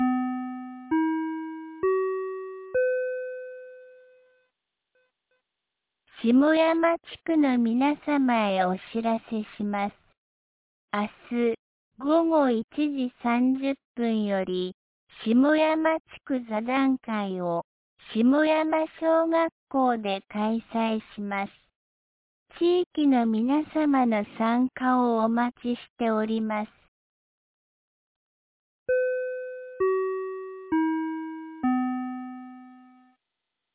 2025年07月09日 18時00分に、安芸市より下山へ放送がありました。